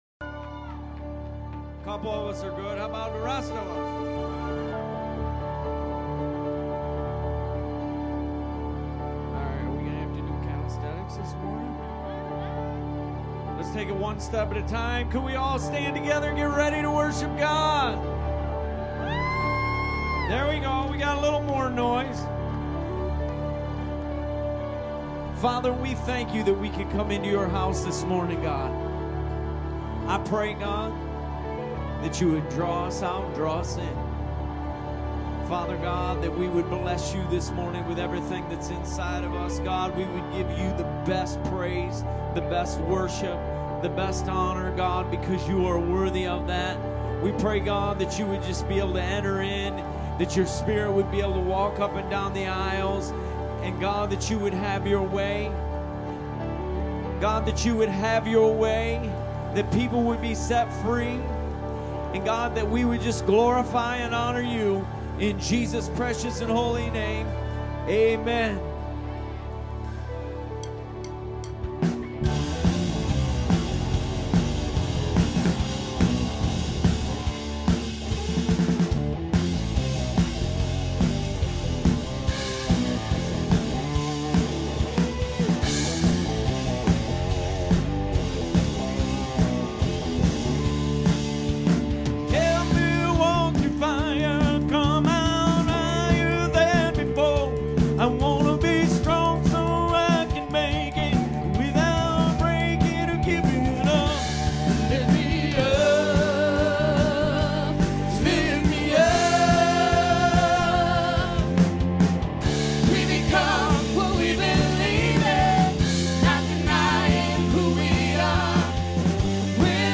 GENEROUS INTERRUPTIONS PT 1 FATHER WANTS TO INTERRUPT YOU! 2ND SERVICE